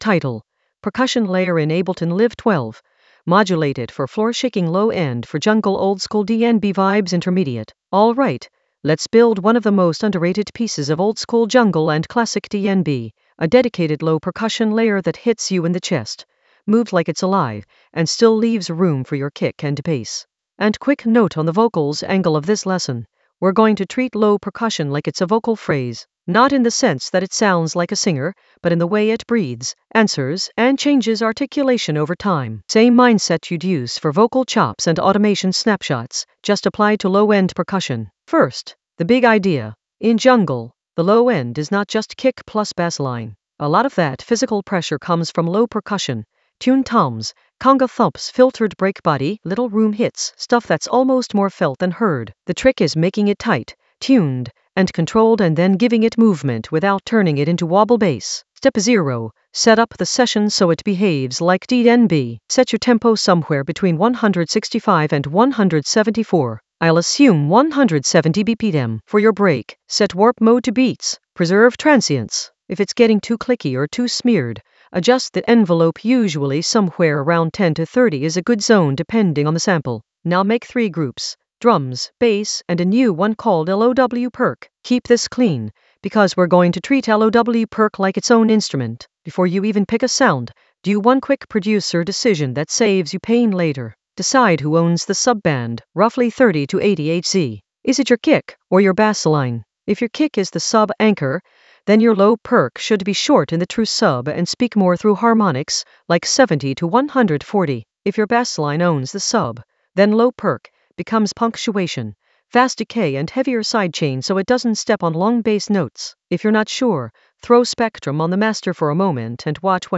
Narrated lesson audio
The voice track includes the tutorial plus extra teacher commentary.
An AI-generated intermediate Ableton lesson focused on Percussion layer in Ableton Live 12: modulate it for floor-shaking low end for jungle oldskool DnB vibes in the Vocals area of drum and bass production.